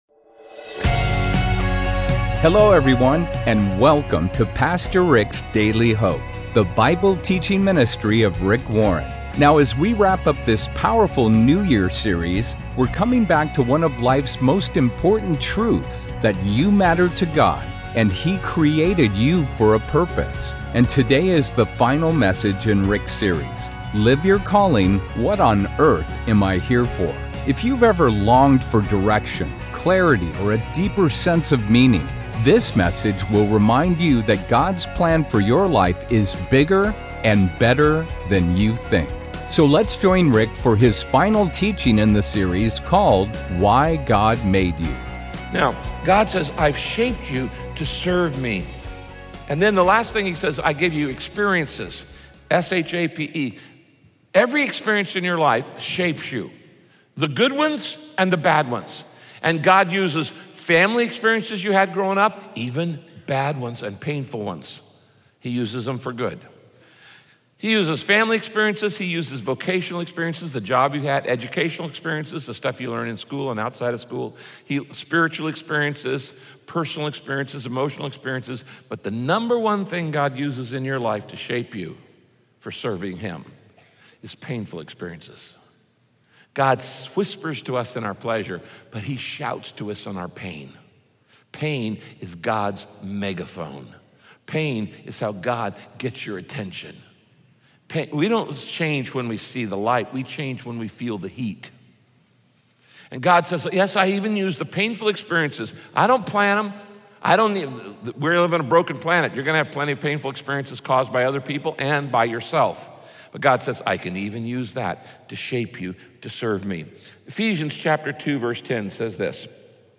Join Pastor Rick as he teaches what the Bible says about why God made you and how he uniquely shaped you to fulfill a specific purpose here on earth.